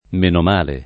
m%no m#le] (raro menomale [id.]) escl.